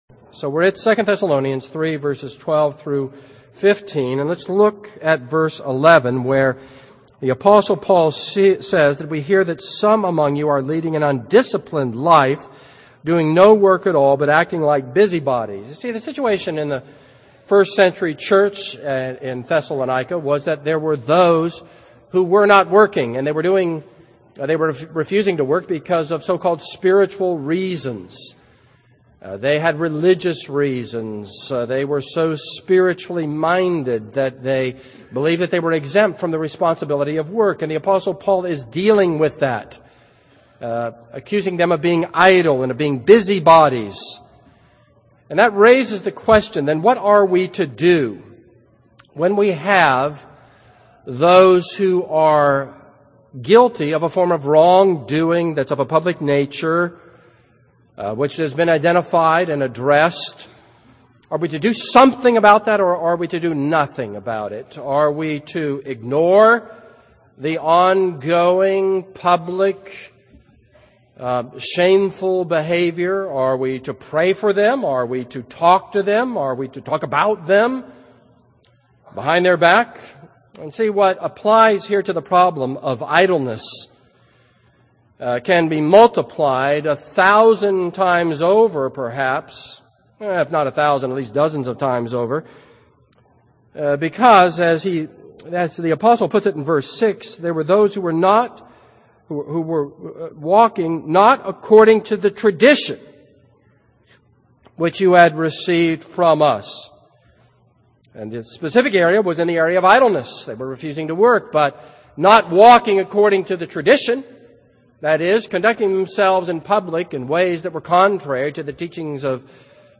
This is a sermon on 2 Thessalonians 3:12-15.